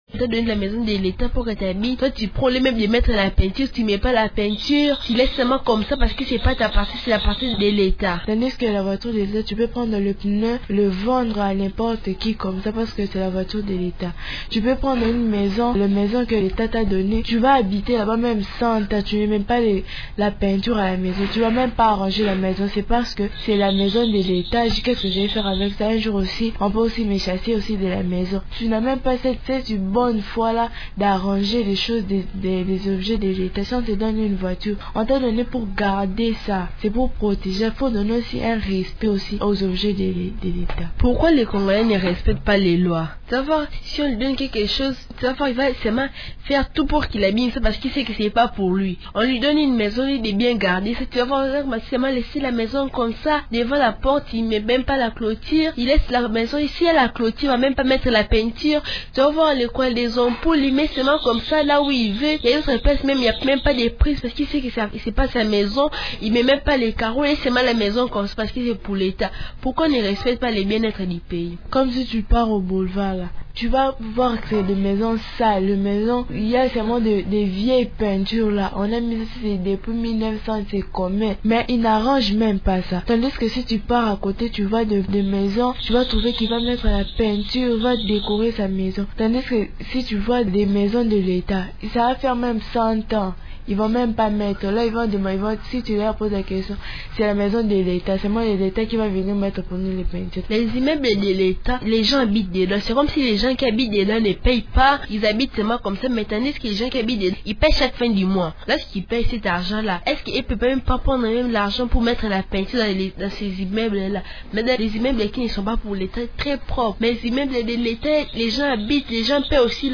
Les enfants parlent du respect des biens publics.